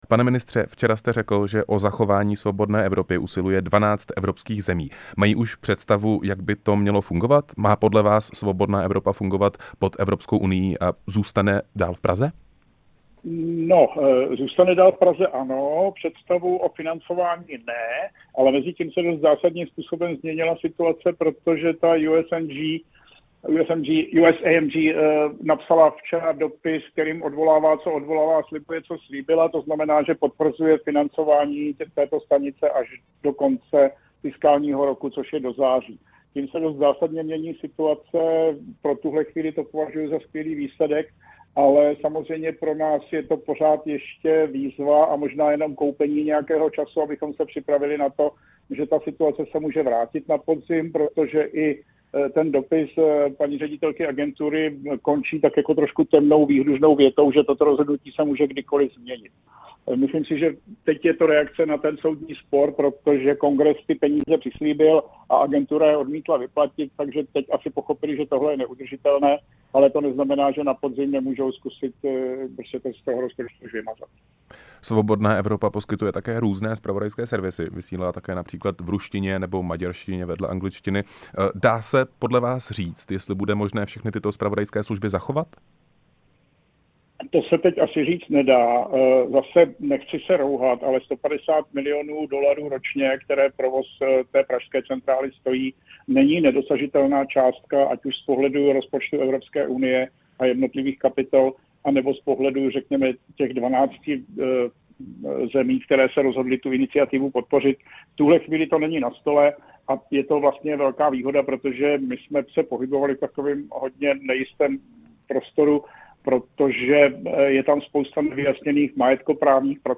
Co si od jednání slibuje Česko? Ve vysílání Radia Prostor jsme se ptali Martina Dvořáka, ministra pro evropské záležitosti.
Rozhovor s ministrem pro evropské záležitosti Martinem Dvořákem